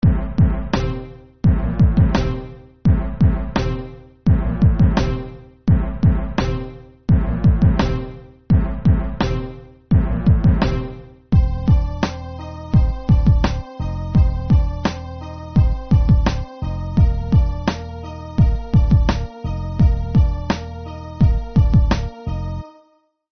(синтезатор); темп (101); продолжительность (2:53)